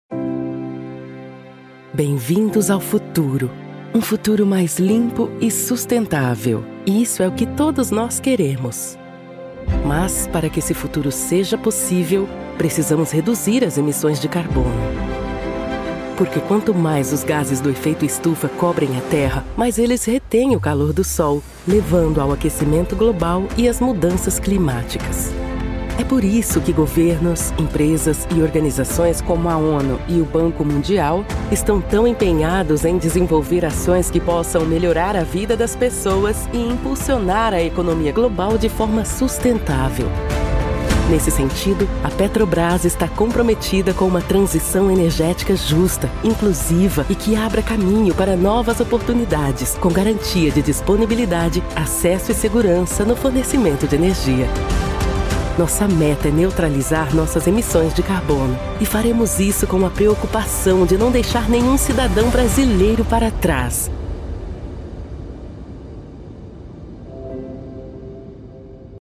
Native Brazilian speaker with a professional recording home studio with high quality equipment, makes your recording clear, natural and clean, without noise interference.
His voice is balanced and corporate, with a relatable grace that brings any project to its absolute peak.
Sprechprobe: Werbung (Muttersprache):
I will save you a natural, mature and convincing voice.
Corporate Internet Ads.mp3